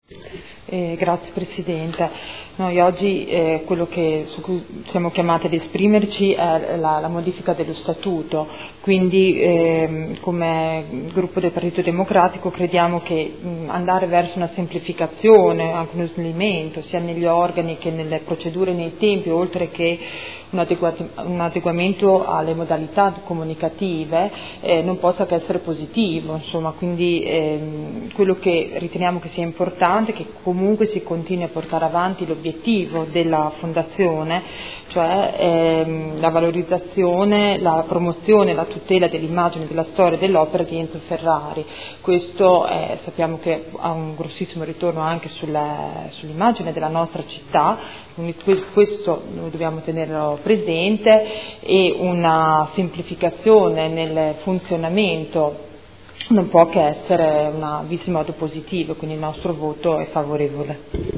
Grazia Baracchi — Sito Audio Consiglio Comunale